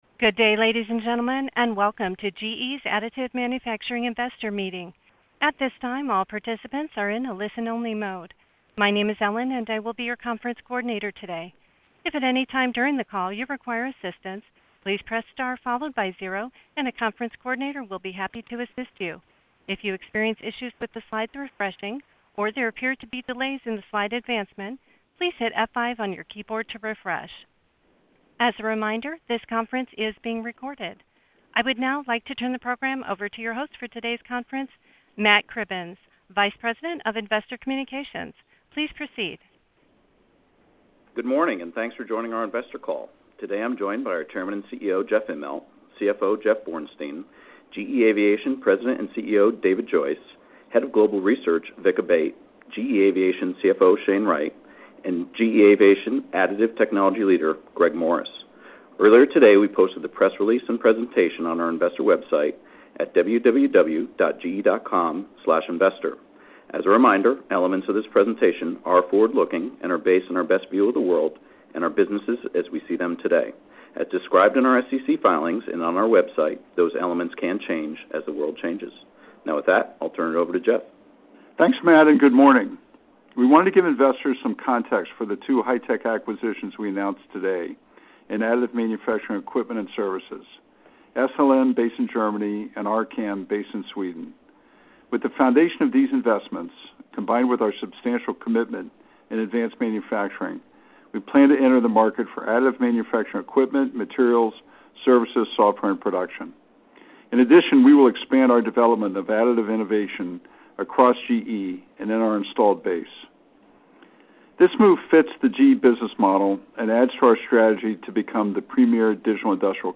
Additive Manufacturing Investor Meeting